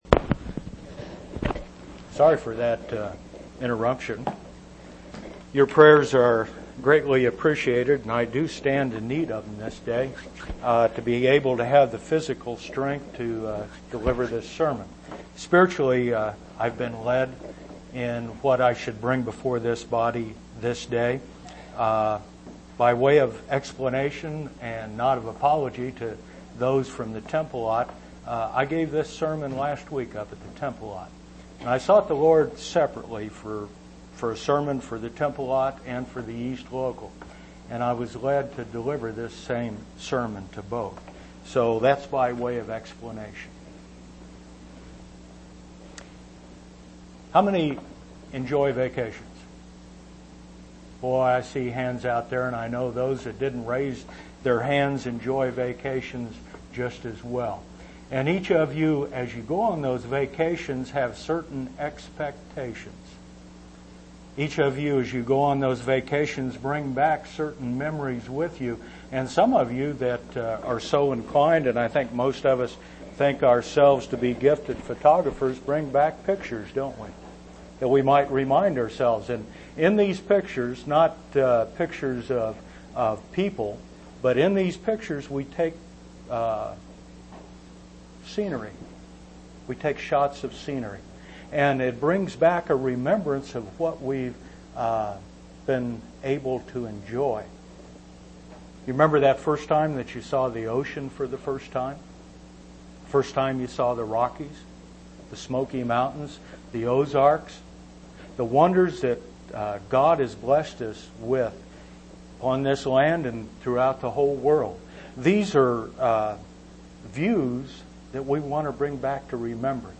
9/27/1998 Location: East Independence Local Event